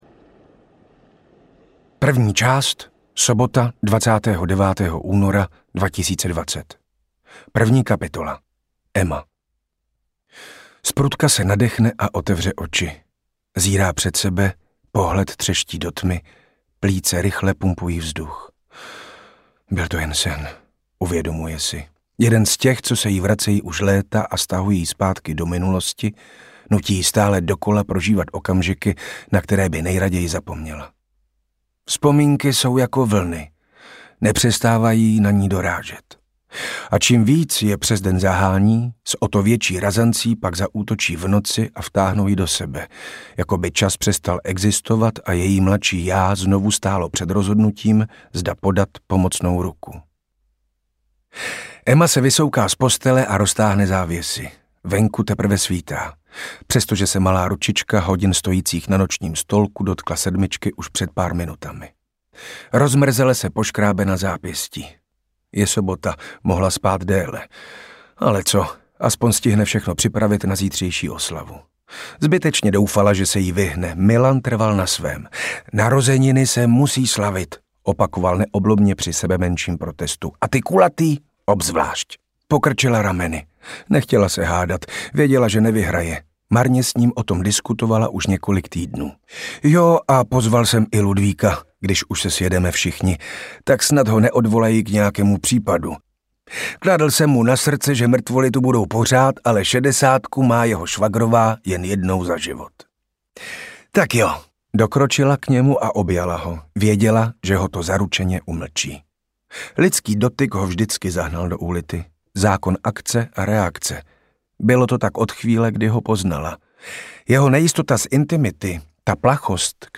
Audiokniha
Čte: Richard Krajčo